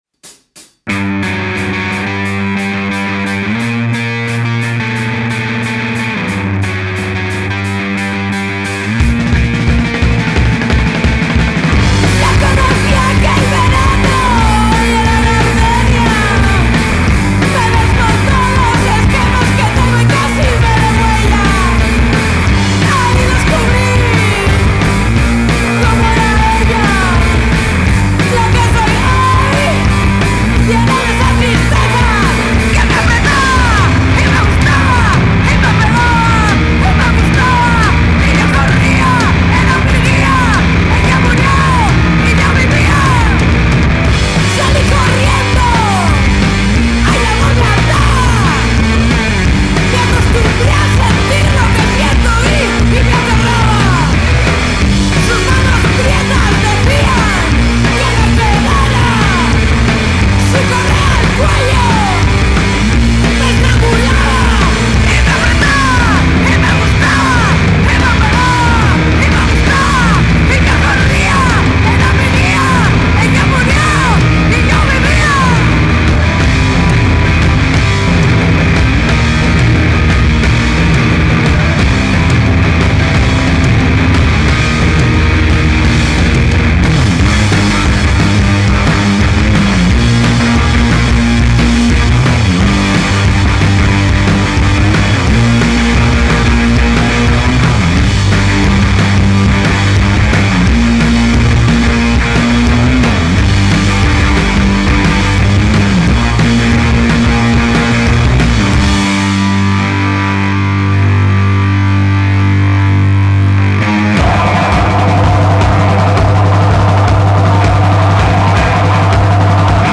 Un debut enérgico y opresivo a partes iguales
Genres: Doom, Noise, Post-Punk, Alternative Rock
Bajo/Bass
Voz/Vocals
Batería/Drums
Guitarra/Guitar